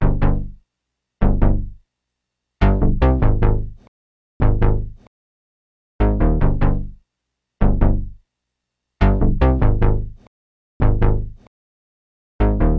撕裂螺丝钉低音合成器
Tag: 150 bpm Trap Loops Bass Synth Loops 2.15 MB wav Key : Unknown Logic Pro